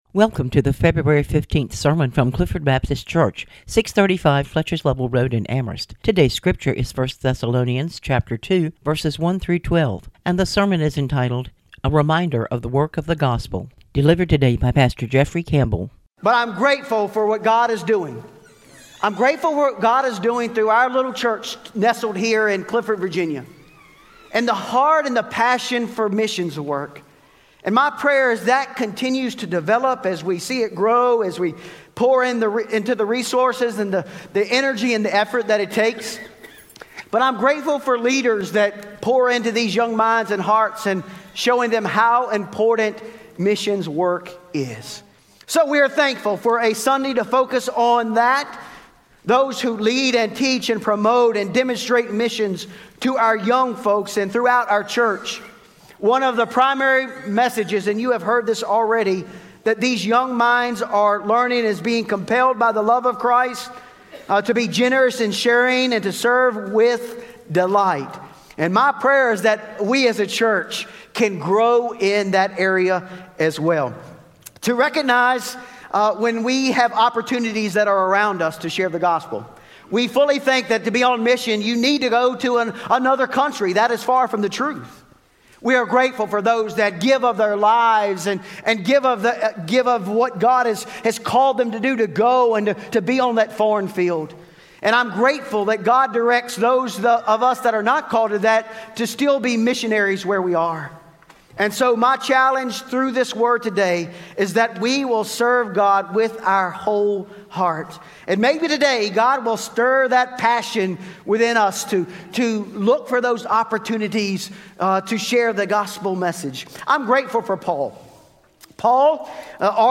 On this WMU Sunday, we are reminded that all of us carry the Gospel.